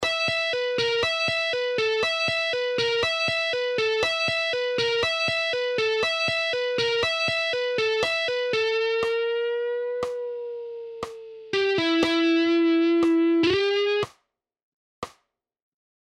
Same lick in half speed:
Half-Speed-Pentatonic-Scale-Guitar-Licks-5.mp3